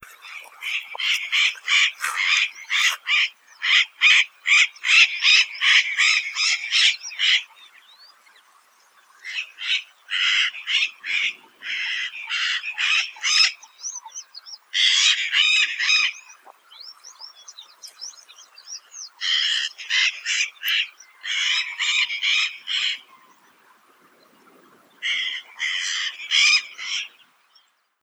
VOZ En voz alta, repite rápidamente cheeah-cheeah.
lorocabezaazul.wav